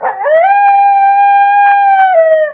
Wolf.ogg